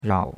rao3.mp3